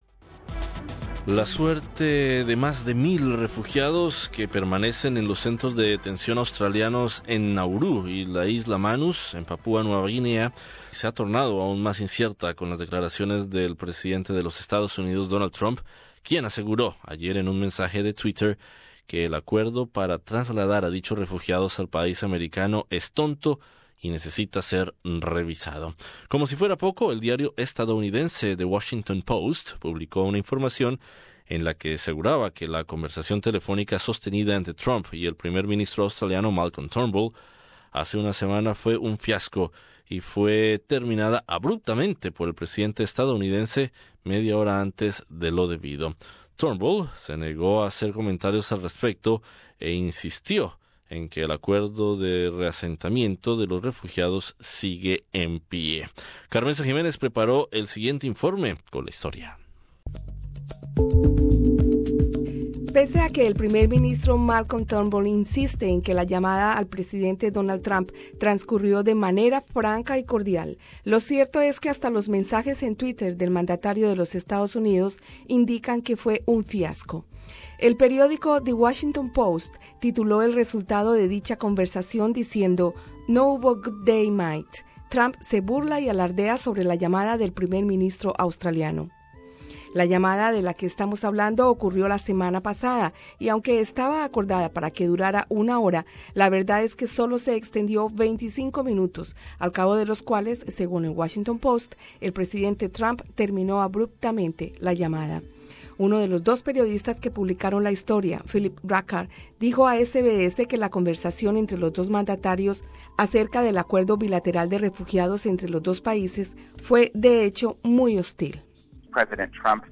Con un tweet, Donald Trump expresó claramente su posición frente al acuerdo de reasentamiento de los refugiados en los centros de Nauru y la Isla Manus y desató una tormenta política alrededor del tema, que incluyó titulares en la prensa de los Estados Unidos. Escucha aquí el informe de SBS español: